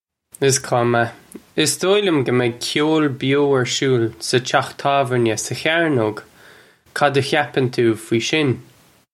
Iss kumma. Iss doy lyum guh meg kyole byo urr shool suh chakh tawvernya suh kharnogue. Kad uh khap-un too fwee shin?
This is an approximate phonetic pronunciation of the phrase.